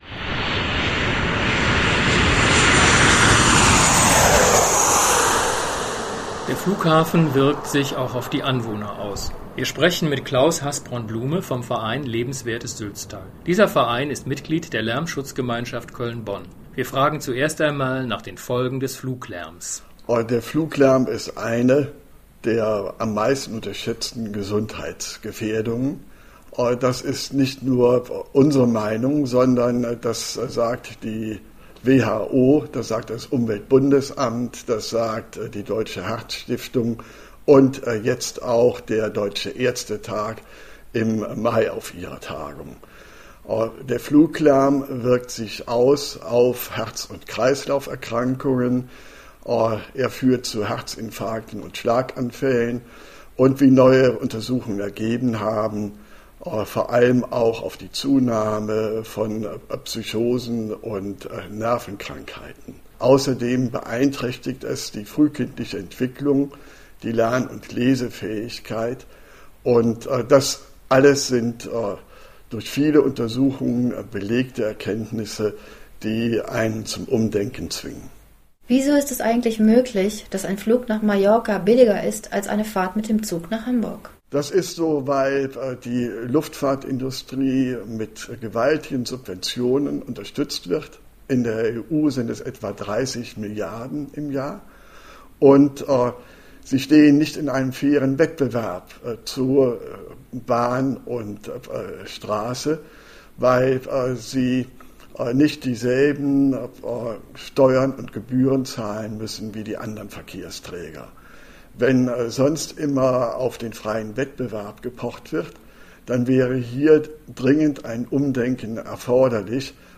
Das Greenpeace Köln Radio sendet regelmäßige Beiträge von Greenpeace Köln im Bürgerfunk bei Radio Köln.